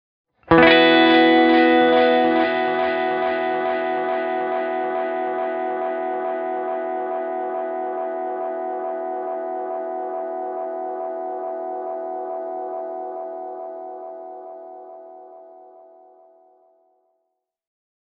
Tältä Flashback X4:n efektityypit kuulostavat:
Lo-Fi
lofi.mp3